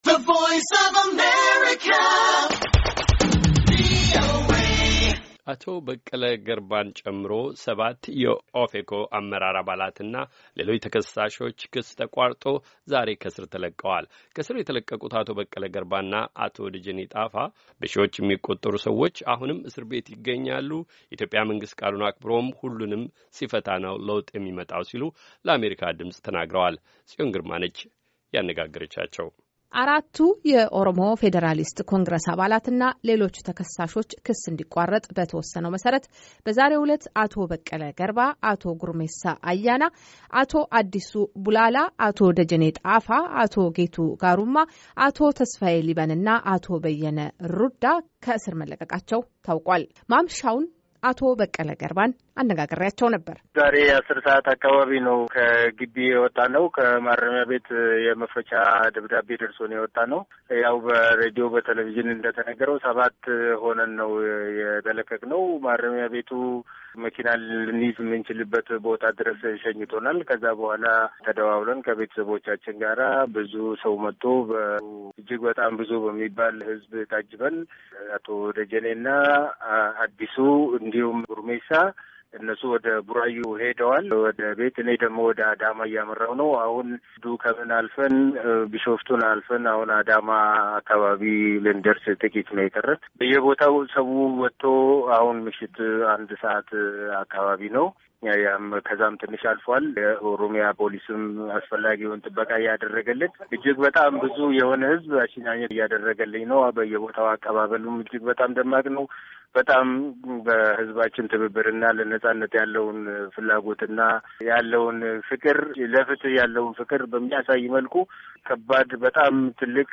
የኦሮሞ ፌድራሊስት ኮንግረስ ምክትል ሊቀመንበር አቶ በቀለ ገርባ ከእስር ከወጡ በኋላ በደጋፊዎቻቸው ታጅበው አዳማ ወደሚገኘው መኖሪያ ቤታቸው በመሄድ ላይ እያሉ በስልክ አግኝተናቸው ነበር።